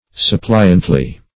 [1913 Webster] -- Sup"pli*ant*ly, adv.